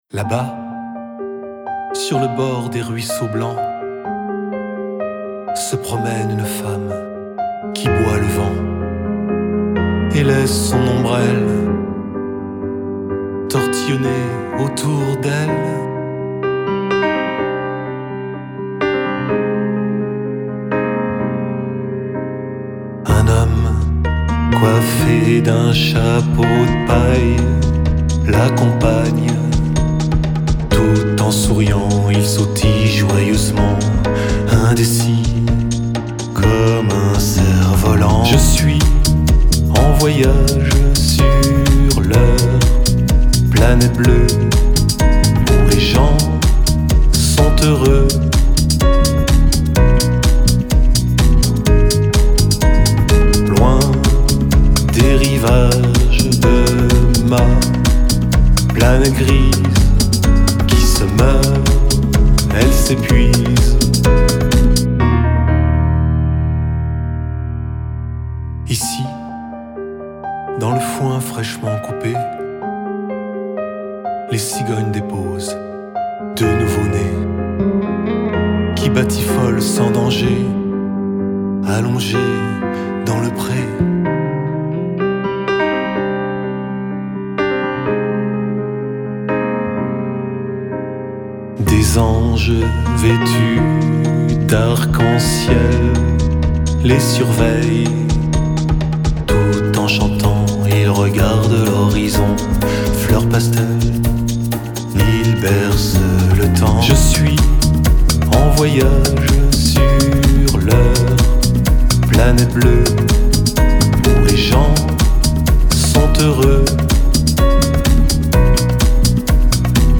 Basse
Boucles musicales : Garage Band et Aturri studio
Chant